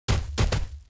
walk_act_1.wav